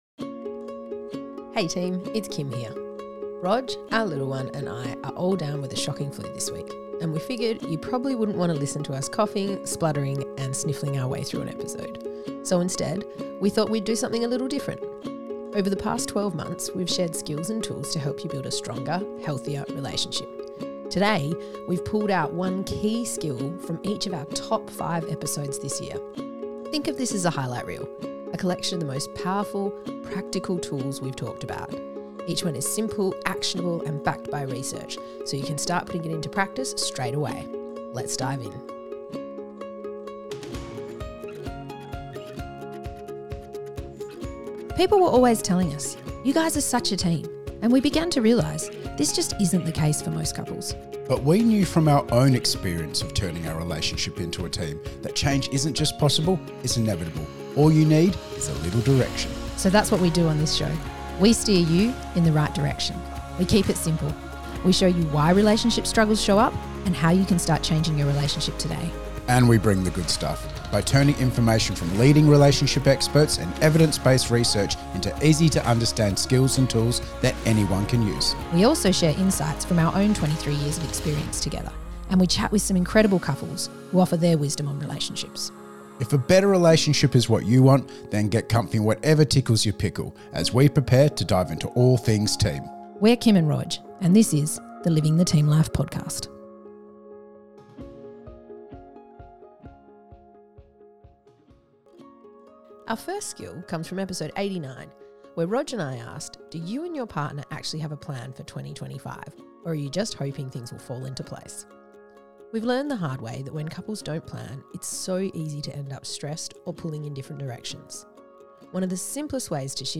Today, we've pulled out one key skill from each of our top five episodes this year. Think of this as a highlight reel.